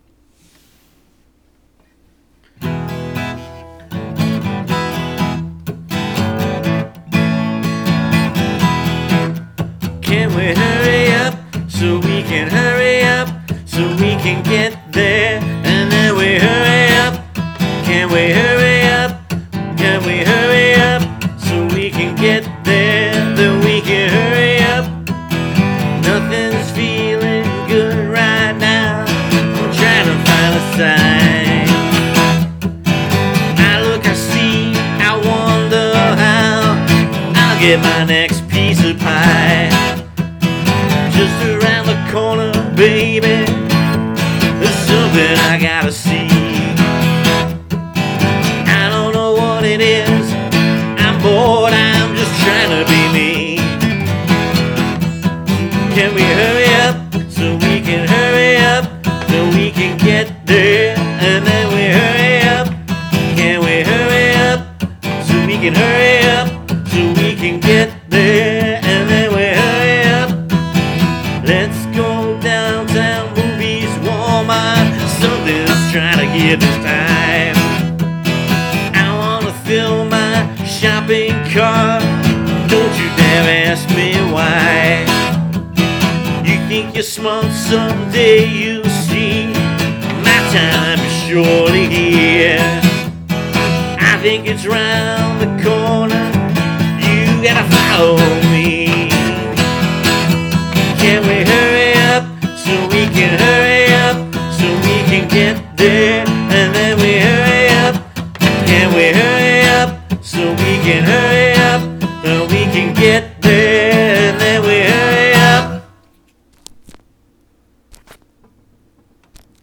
Introducing a collection of original rock songs recorded between 2006 and 2018!
Although initially recorded on an old Tascam 2-track recorder, (or my phone), in my living room, with some catchy melodies and heartfelt lyrics, these demos encapsulate the essence of my style of rock music.